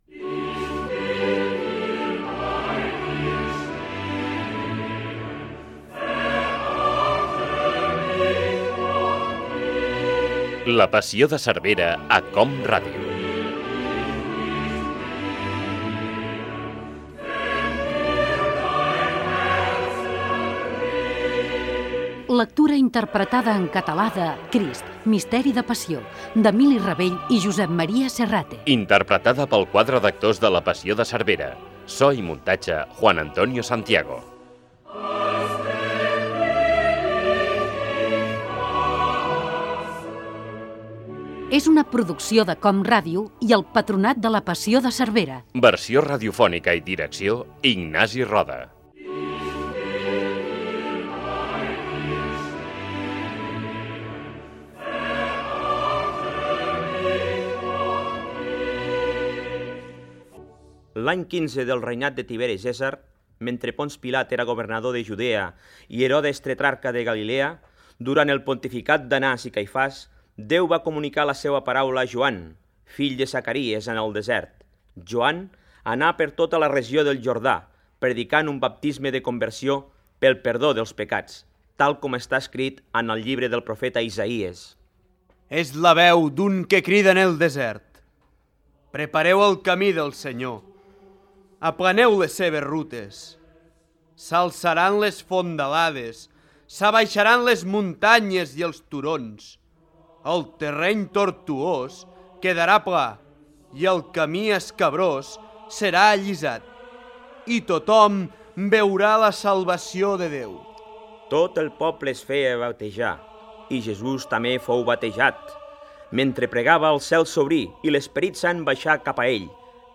Careta del programa, contextualització històrica i primeres minuts de l'obra.
Ficció